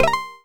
Achievement Unlocked and Leaderboard Submitted sound effects
sfx_achievement_unlocked.wav